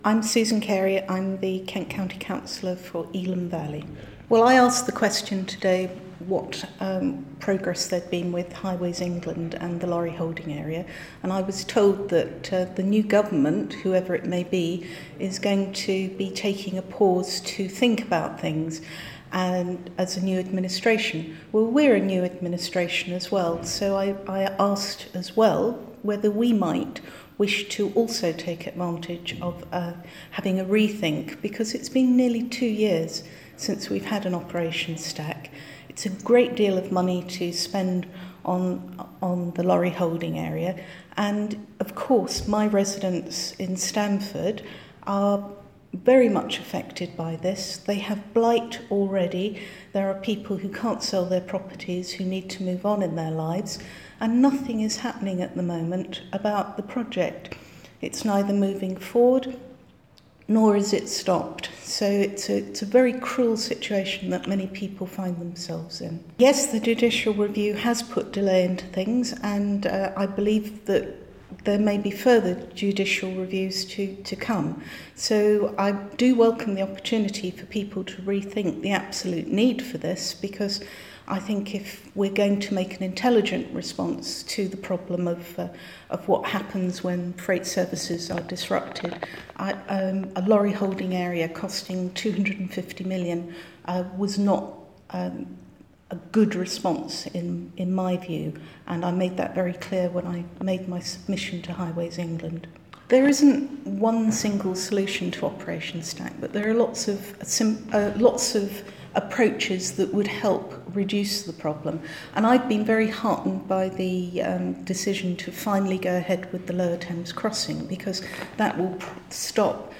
INTERVIEW: Cllr Susan Carey - Operation Stack